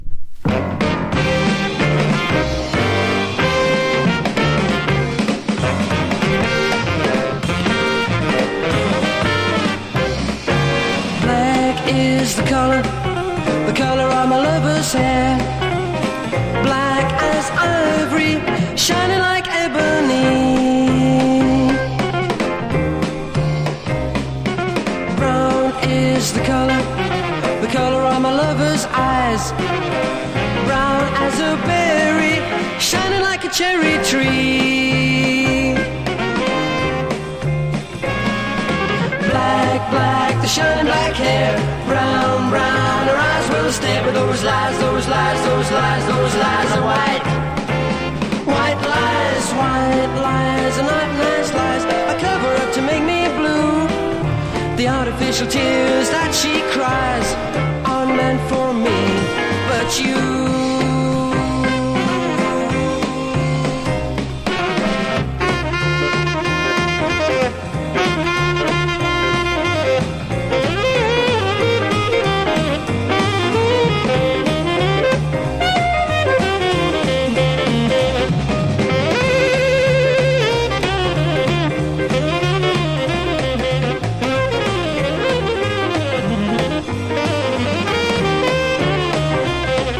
イギリスのポップ・デュオによる66年作品。